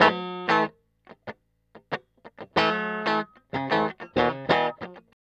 TELEDUAL A#2.wav